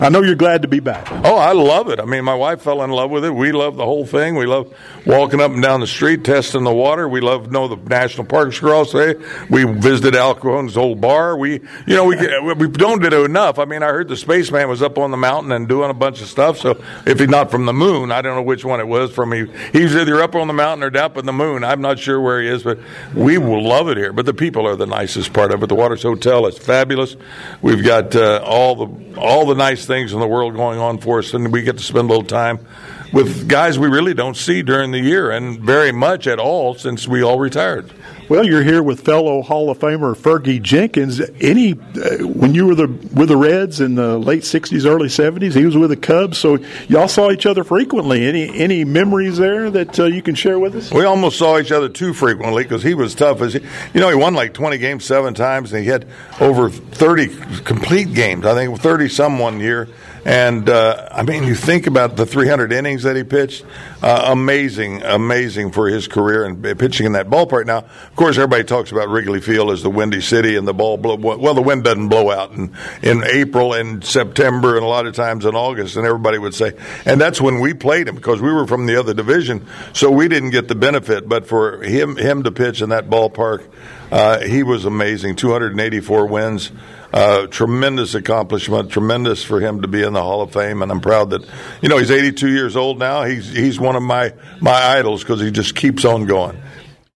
Hall of famers, baseball legends draw fans to Hot Springs for annual baseball weekend